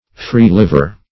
Free-liver \Free"-liv`er\, n.